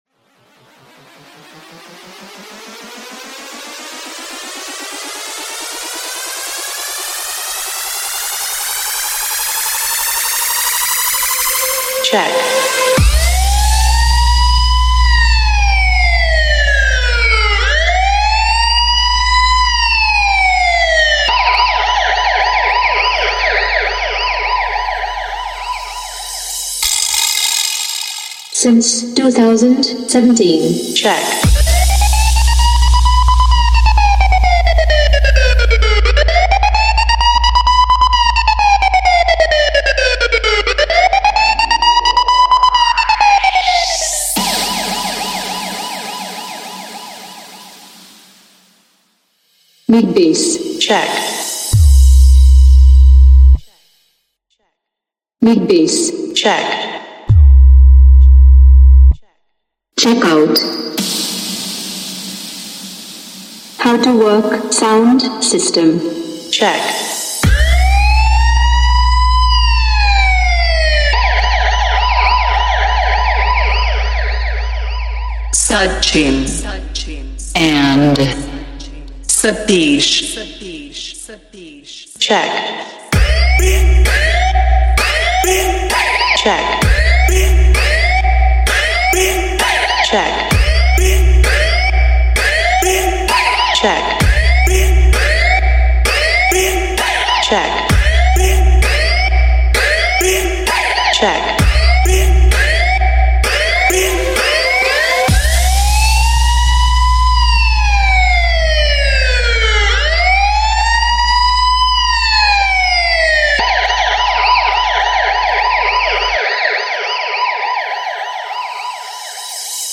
آهنگ پلیسی شوتی آژیر خطر ریمیکس باکلام سیستم صوتی ماشین